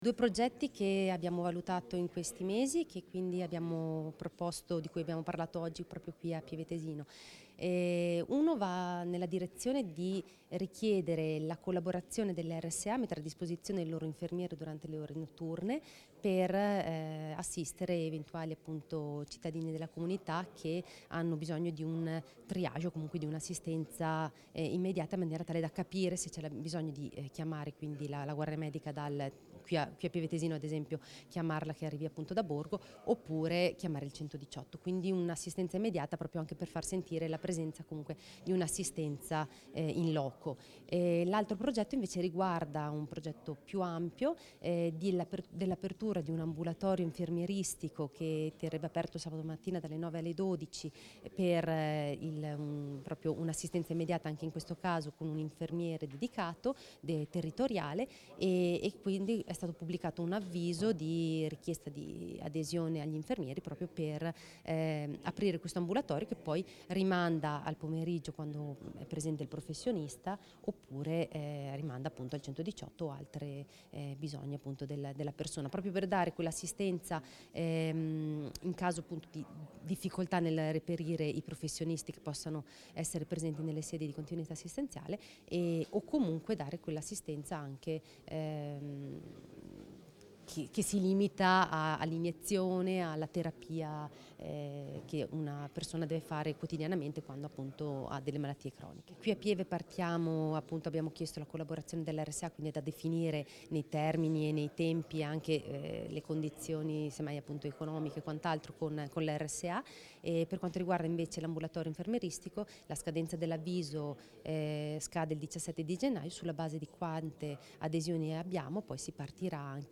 Intervista assessore Segnana: (at) Fonte: Ufficio Stampa Salute e benessere Versione Stampabile Immagini Visualizza Visualizza Audio SEGNANA (4) Scarica il file (File audio/mpeg 3,98 MB)